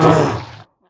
Minecraft Version Minecraft Version 1.21.4 Latest Release | Latest Snapshot 1.21.4 / assets / minecraft / sounds / mob / polarbear / hurt3.ogg Compare With Compare With Latest Release | Latest Snapshot
hurt3.ogg